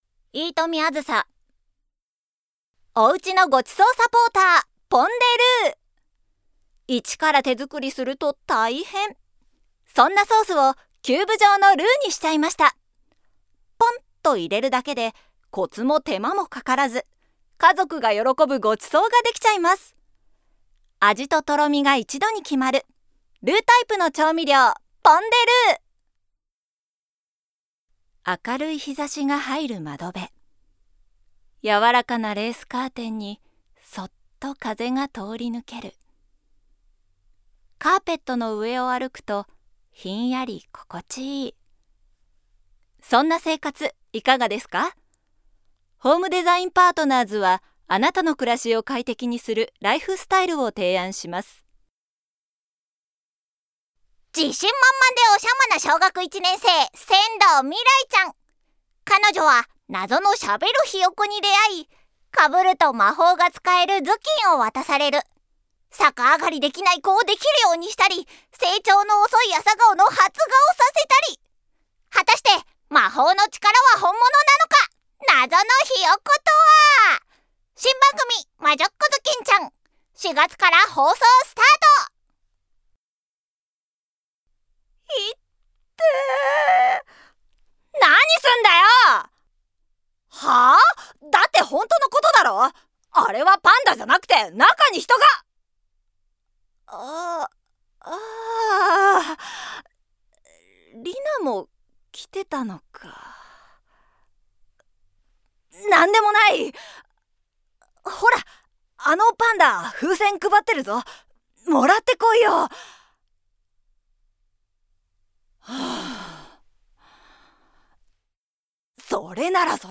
音域： F#3～B4
方言： 名古屋(尾張)弁
VOICE SAMPLE